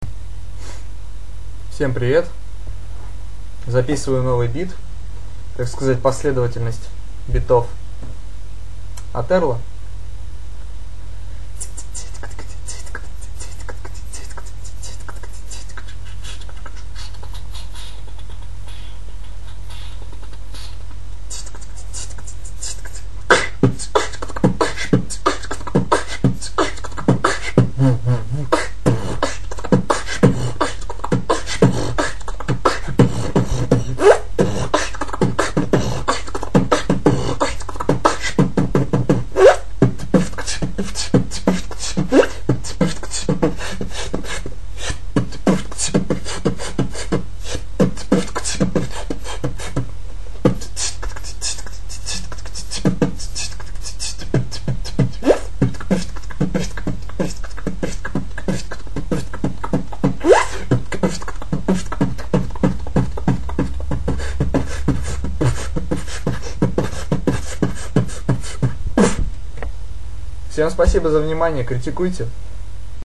t t ts tktk t ts tktk
Kch b t kch tk tk b kch
Хендклеп очень хороший.